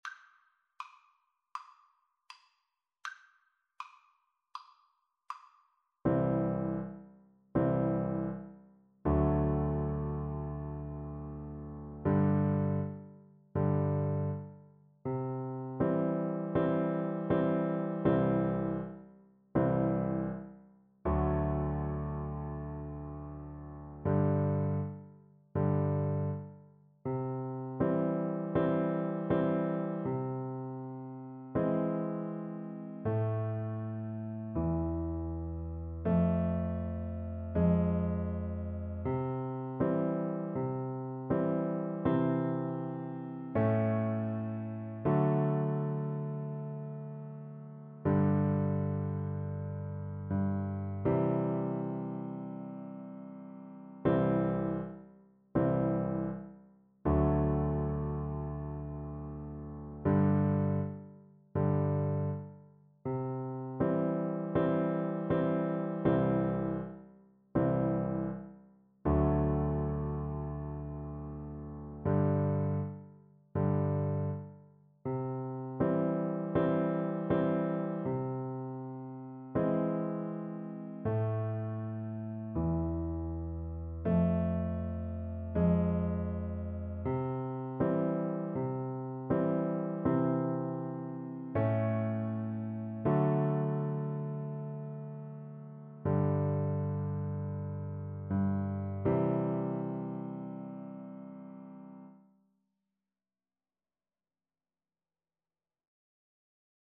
C major (Sounding Pitch) (View more C major Music for Piano Duet )
Andante = c. 80
Piano Duet  (View more Easy Piano Duet Music)